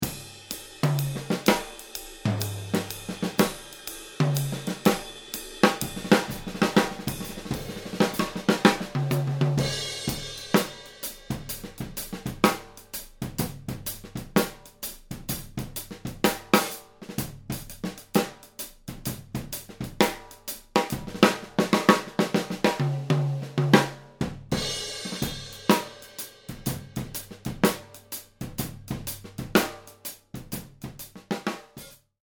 Overhead mics (583 KB)
The first mix uses only the overhead mics. Note how the snare and toms sound honest and straightforward. On the other hand, the kick drum is poorly recorded by the overheads.
All sound files were mixed without any EQ and without any processing other than limiting and MP3 conversion.
overheads-only.mp3